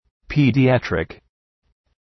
Προφορά
{,pi:dı’ætrık} (Επίθετο) ● παιδιατρικός